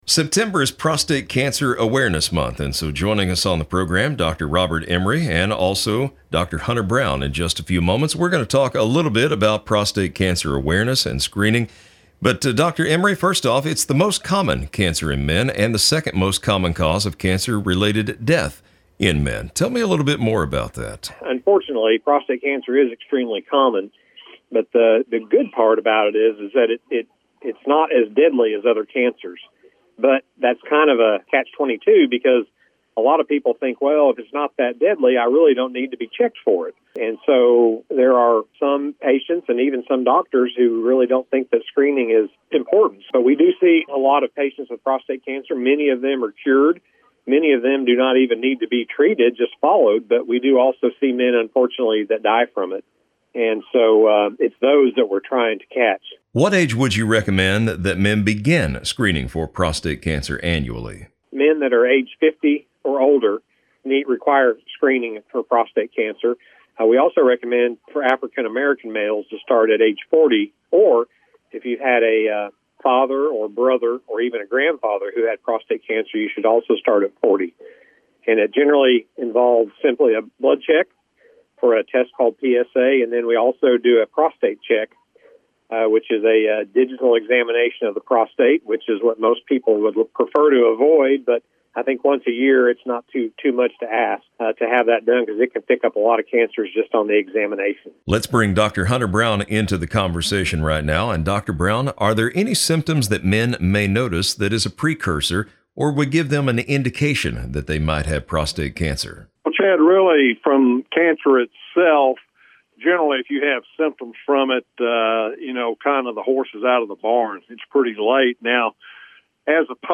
Prostate-Cancer-Awareness-Month-Interview.mp3